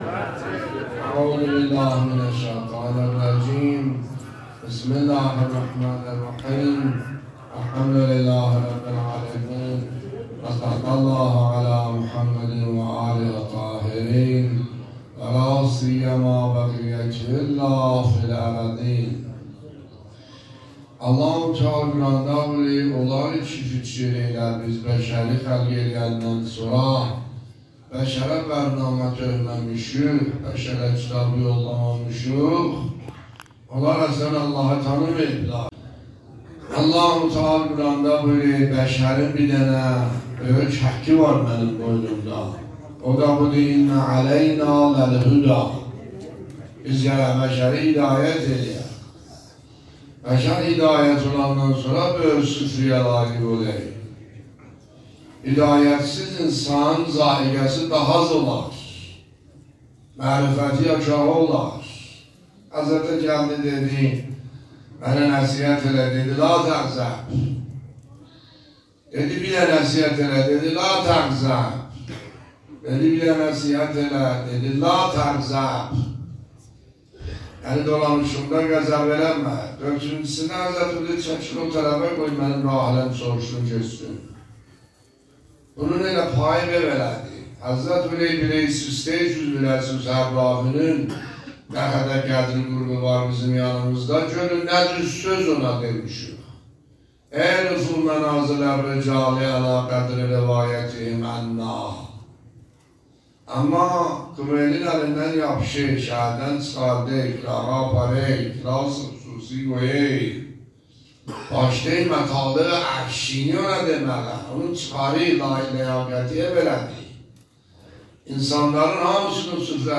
دانلود و پخش آنلاین سخنرانی آیت الله سید حسن عاملی در هفتمین روز ماه مبارک رمضان 1402 در مسجد میرزا علی اکبر
بیانات آیت الله سید حسن عاملی در هفتمین روز ماه مبارک رمضان 1402 در مسجد میرزا علی اکبر درباره ی «هدایت الهی» به مدت 43 دقیقه